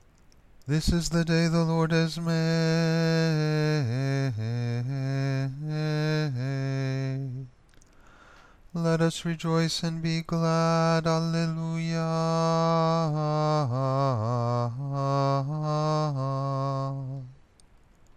Caveat: this is not Gregorian Chant.
Responsories